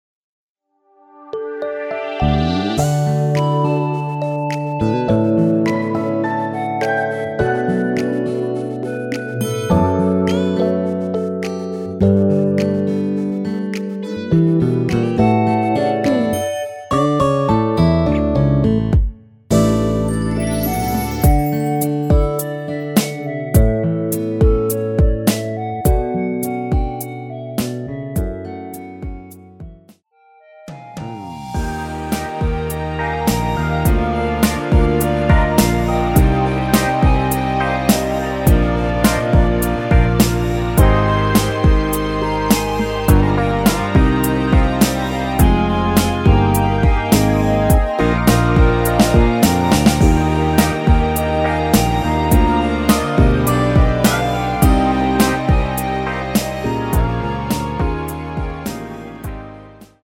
멜로디 MR이라고 합니다.
앞부분30초, 뒷부분30초씩 편집해서 올려 드리고 있습니다.
중간에 음이 끈어지고 다시 나오는 이유는